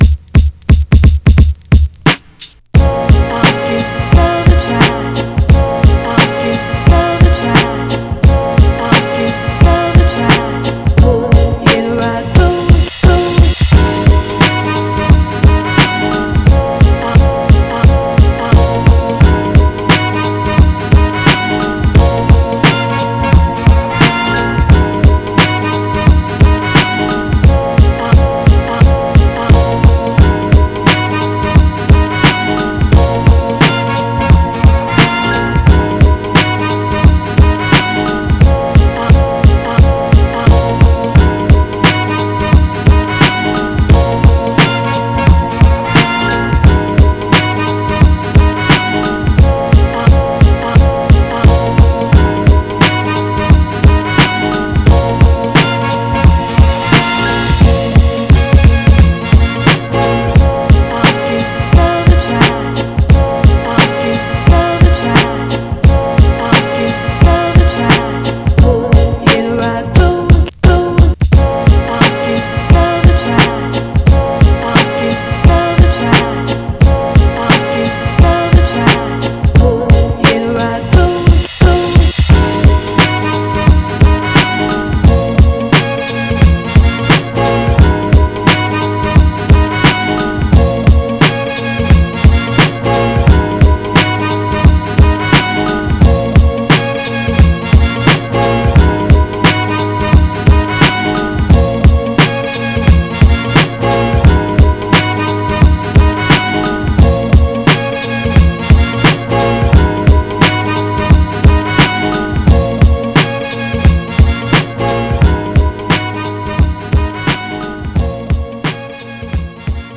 instrumental album